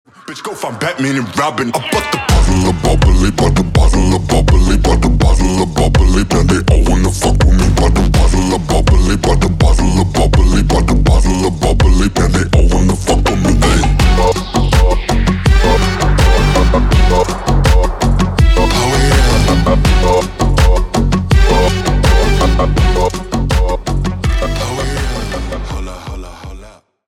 • Качество: 320, Stereo
ритмичные
Midtempo